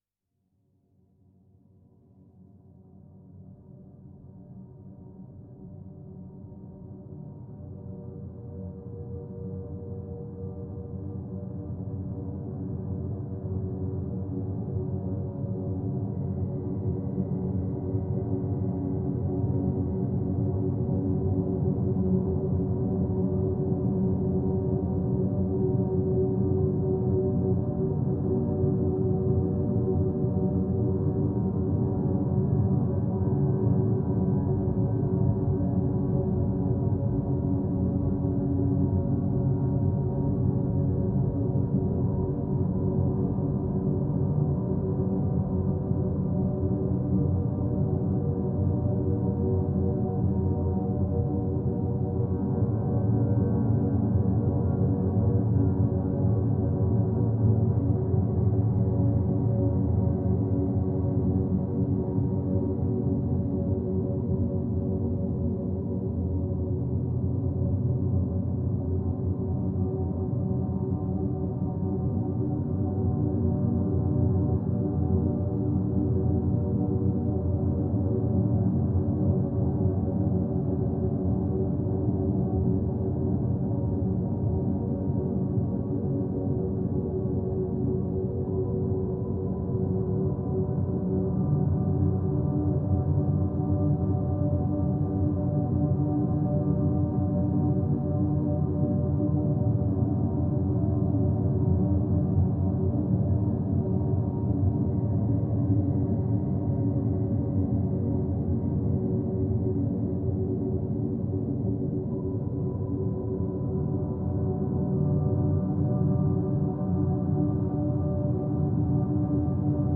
Dark synth ambience with a gloomy, heavy feeling.